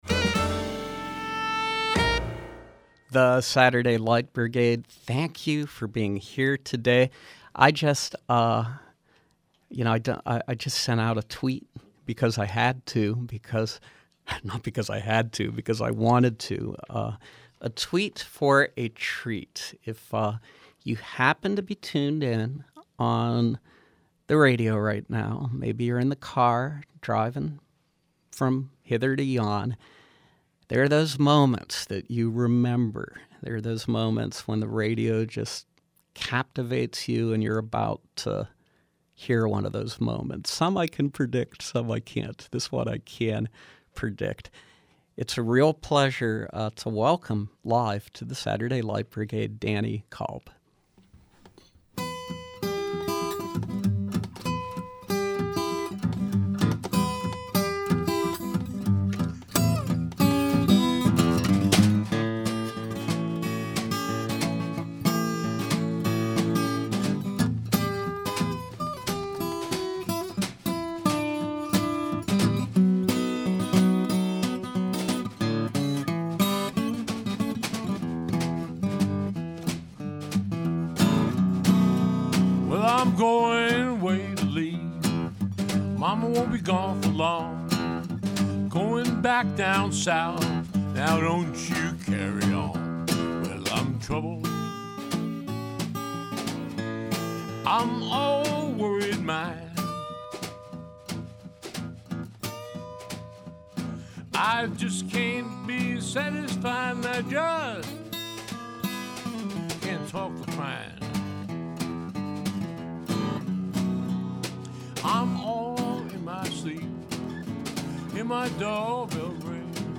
guitar
playing live in our studios